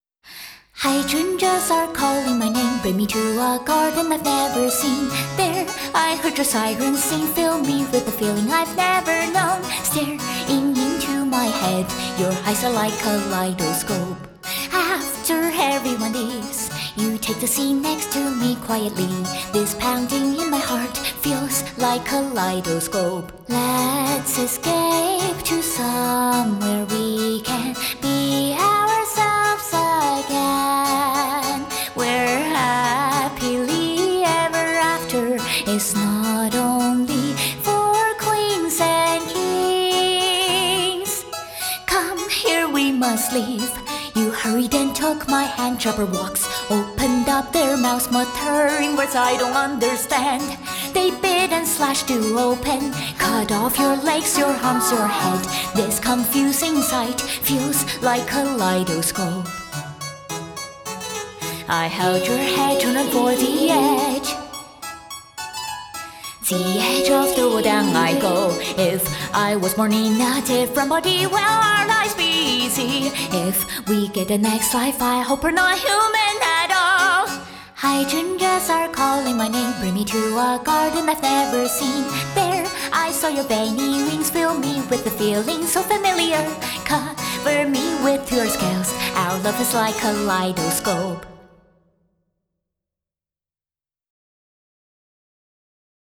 Vocal+Piano Unplugged